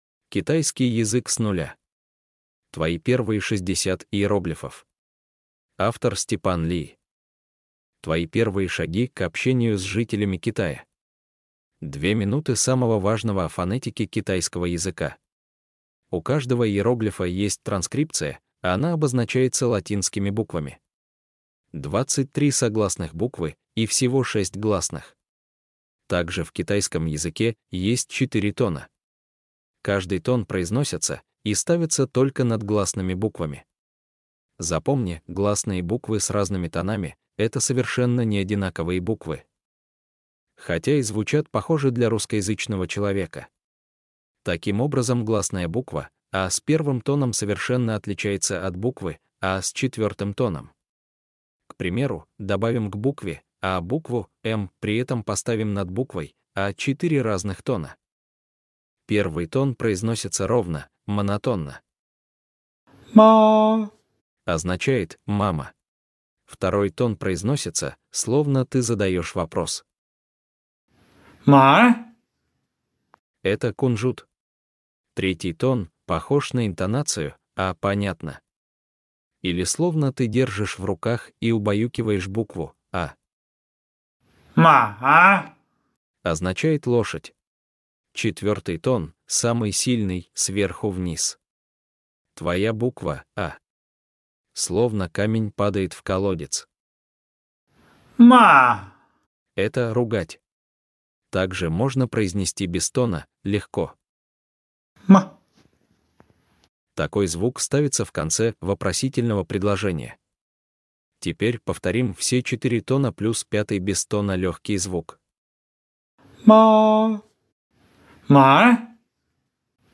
Аудиокнига Китайский язык с нуля. «Твои первые 60 иероглифов» | Библиотека аудиокниг